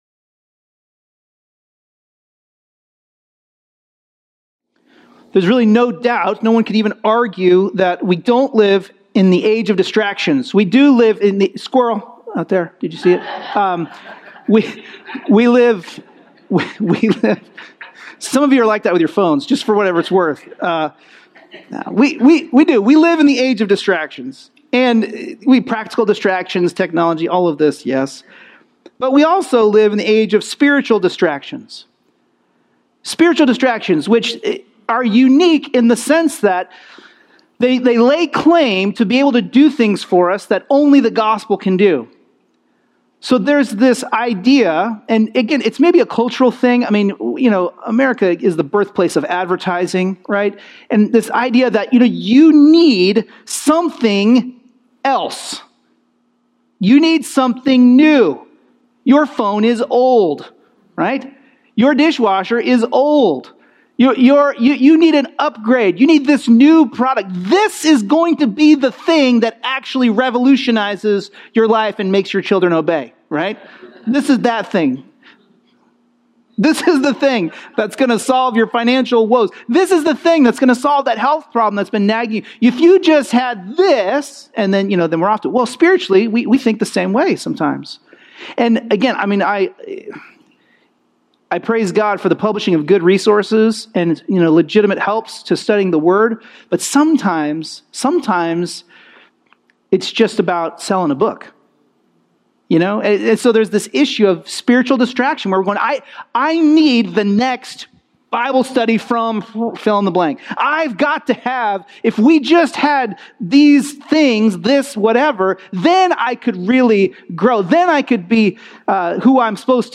An expositional preaching series through Paul's first letter to the church at Corinth.